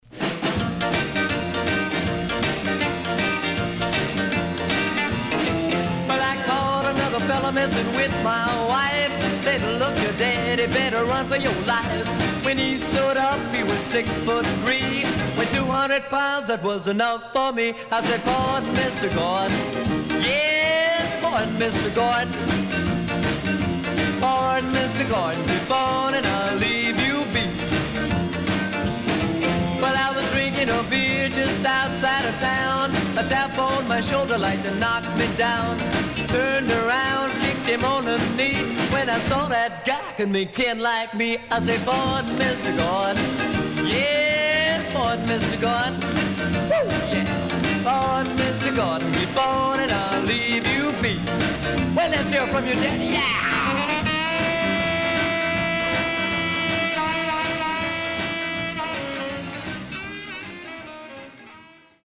HOME > SOUL / OTHERS
SIDE A:ノイズあまり反映しません。